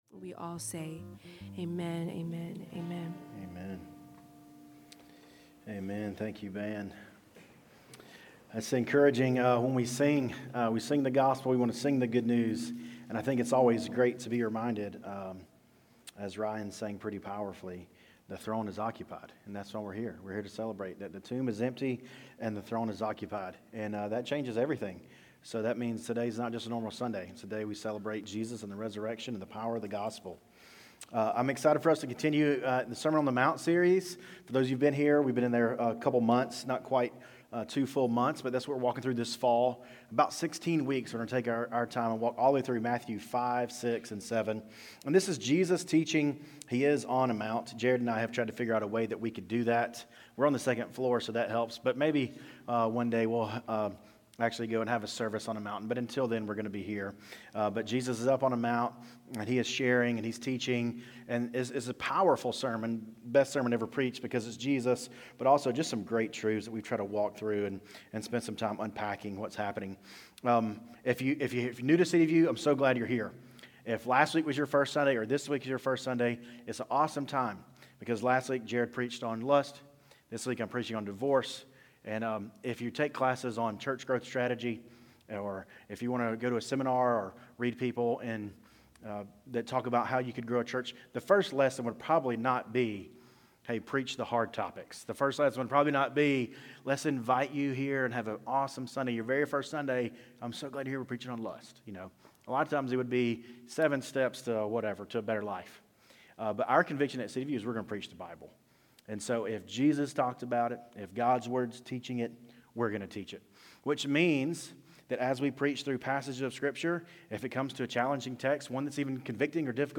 City View Church - Sermons